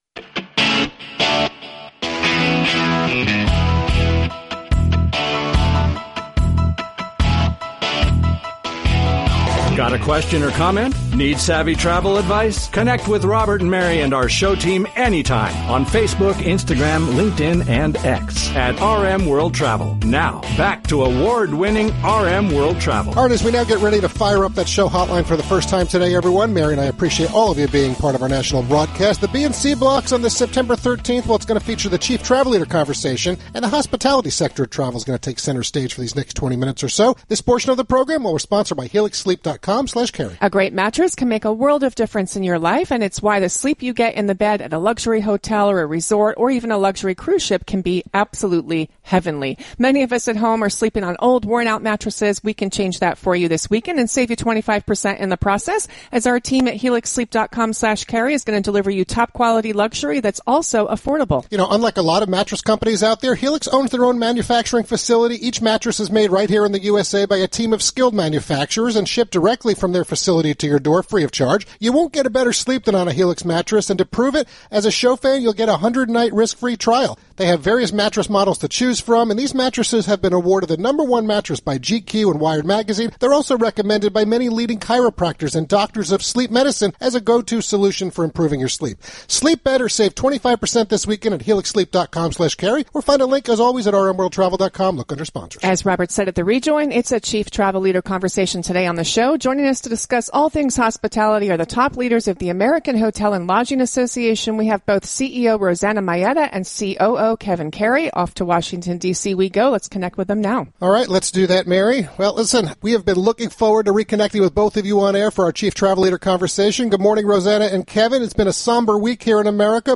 Since 2020, the leaders of the American Hotel & Lodging Association have appeared with regularity on America’s #1 Travel Radio Show, and the conversation is always informative, engaging, timely and robust. AHLA represents every segment of the Hotel & Lodging Sector of Travel nationwide, with more than 32,000 member properties, in excess of 1000 corporate members and the Top-10 hotel brands in the industry.